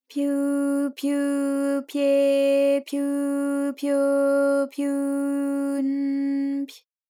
ALYS-DB-001-JPN - First Japanese UTAU vocal library of ALYS.
pyu_pyu_pye_pyu_pyo_pyu_n_py.wav